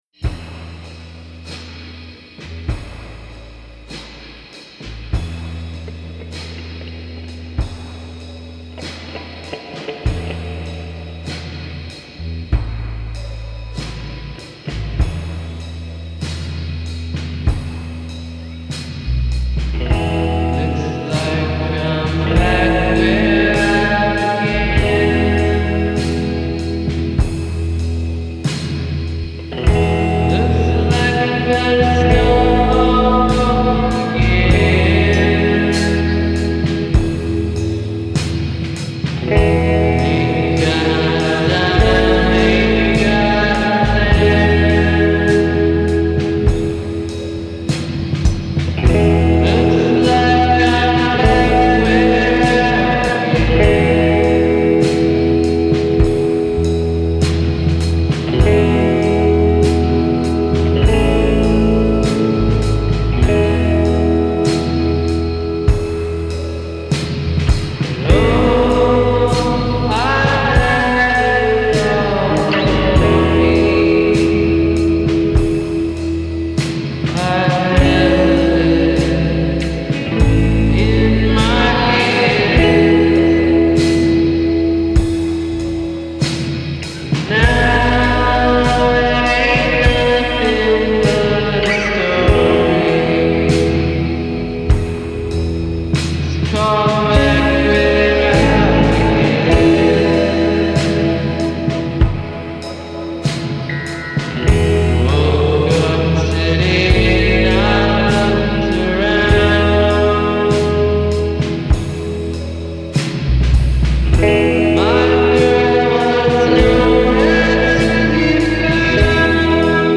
soaked in effects pop
slow, hazy pop number